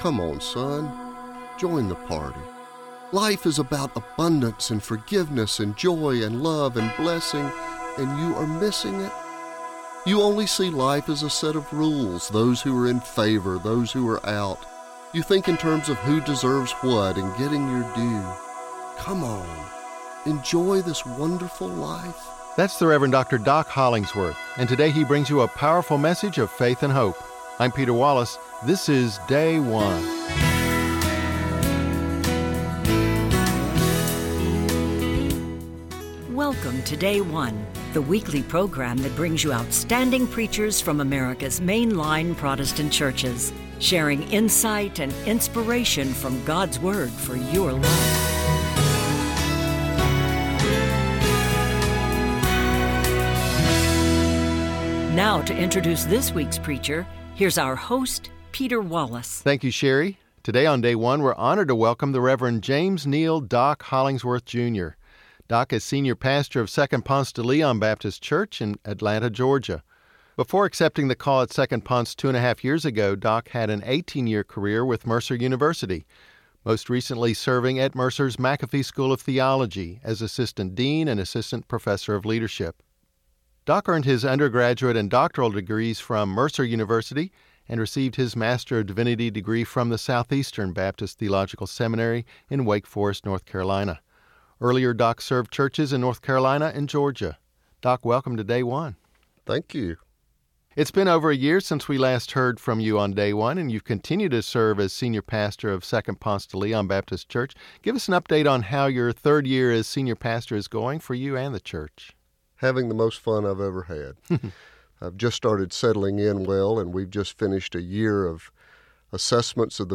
Cooperative Baptist Fellowship 4th Sunday in Lent - Year C Luke 15:1-3,11b-32